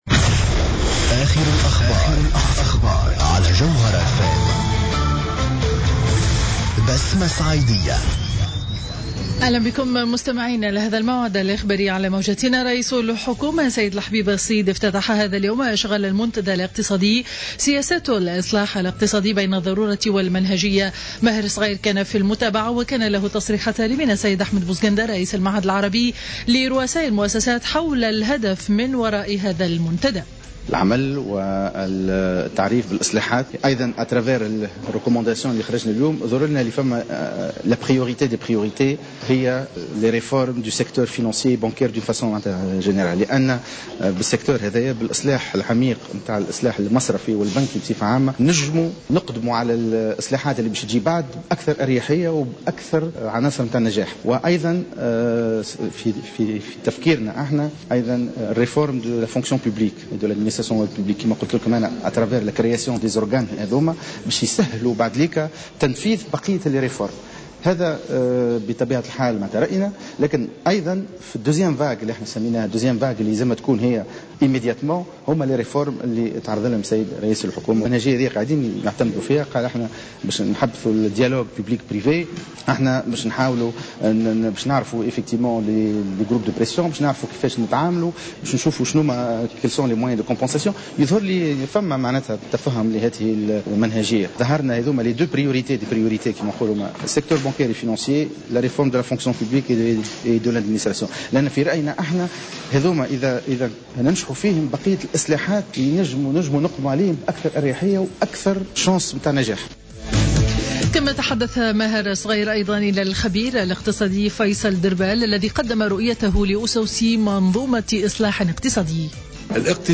نشرة أخبار منتصف النهار ليوم الخميس 12 مارس 2015